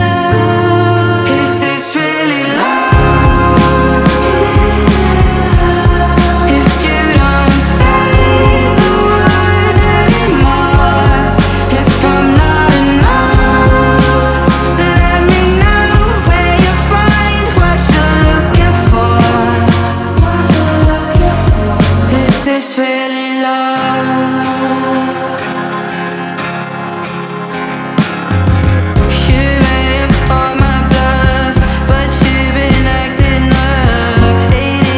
2025-04-25 Жанр: Альтернатива Длительность